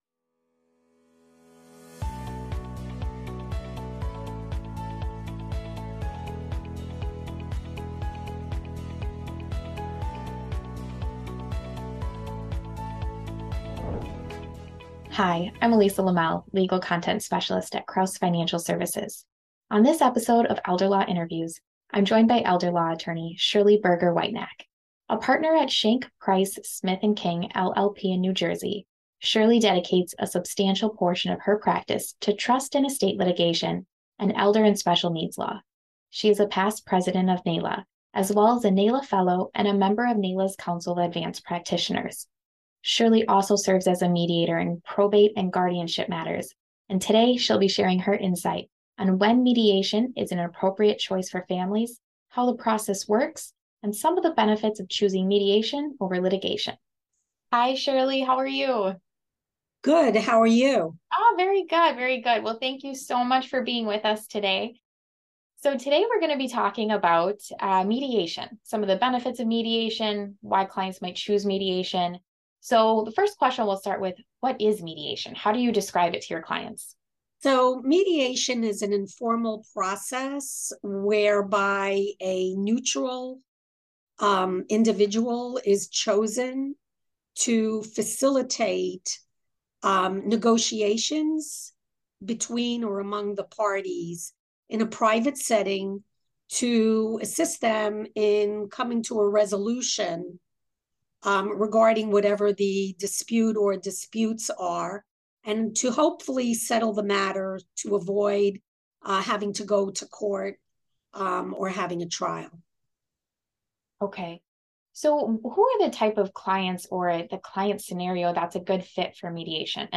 Download Audio Version In this Elder Law Interview